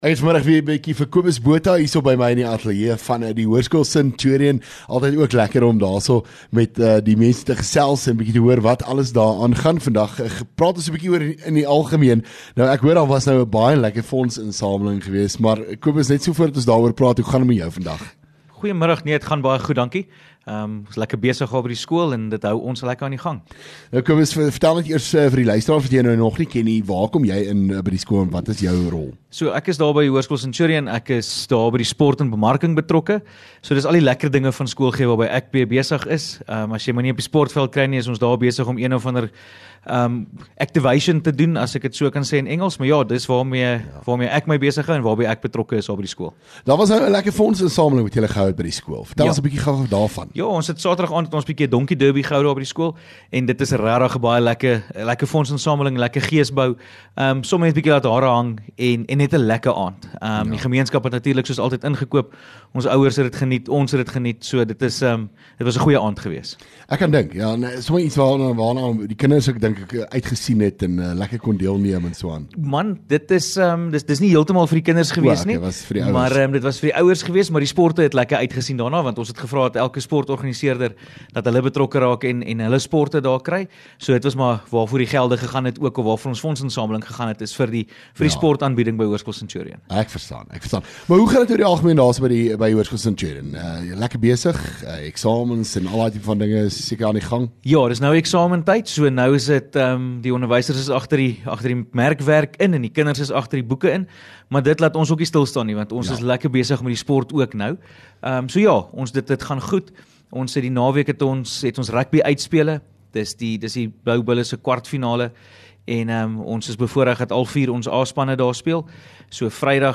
LEKKER FM | Onderhoude 3 Jun Hoerskool Centurion